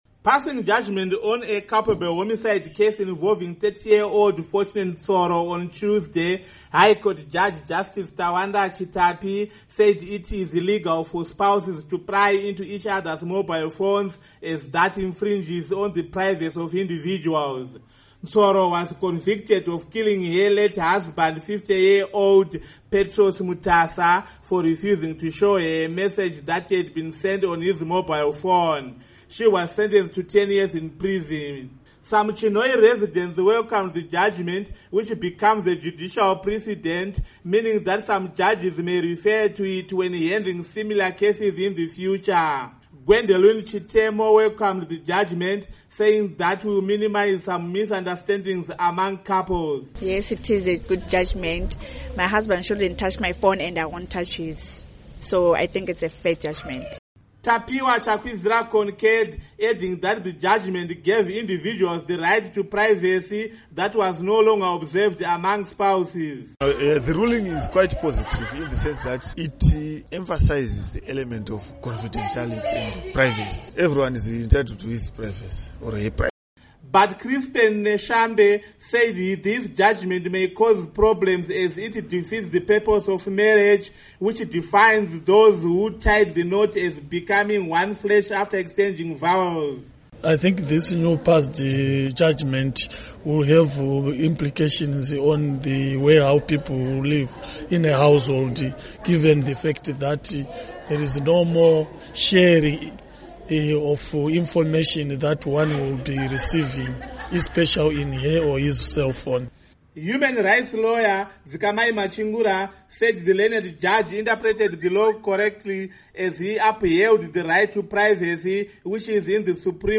Report on Mobile Phone Use